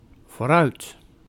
Vooruit ([voːrˈœyt]
Nl-vooruit.ogg.mp3